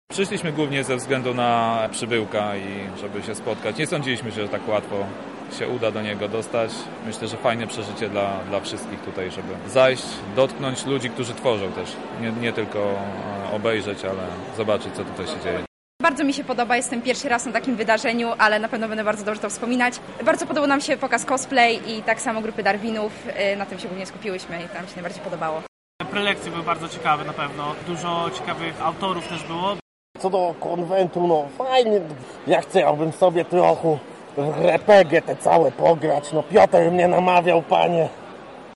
Uczestnicy podzielili się swoim wrażeniami z naszym reporterem: